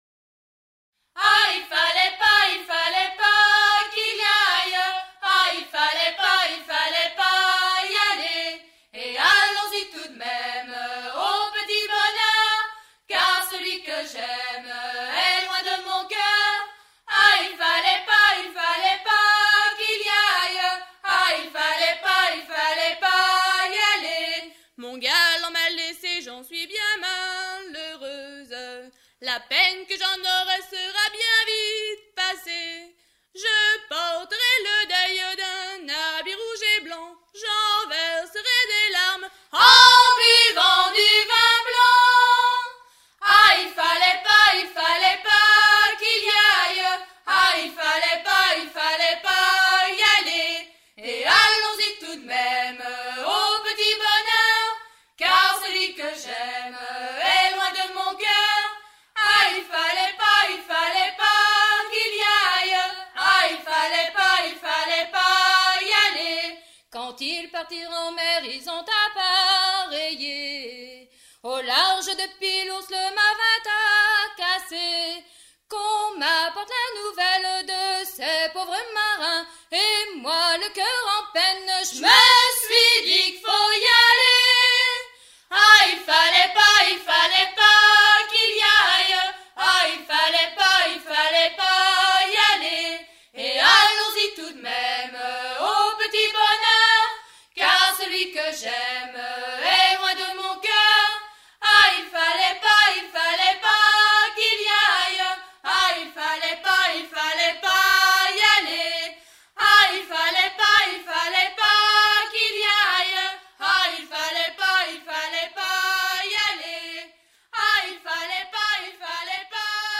Chant donné comme étant celui des ouvrières ou sardinières pour leur retour de la conserverie
Pièce musicale éditée